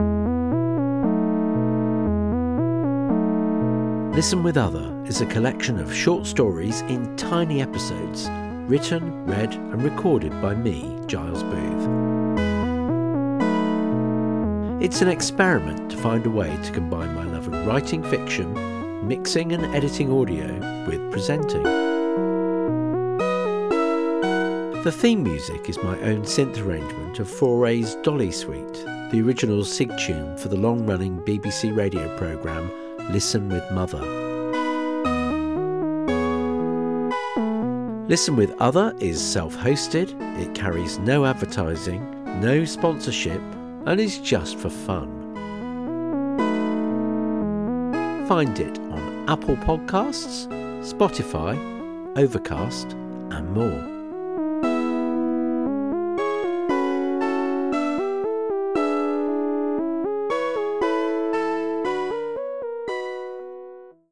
Narrated Multigenre Anthology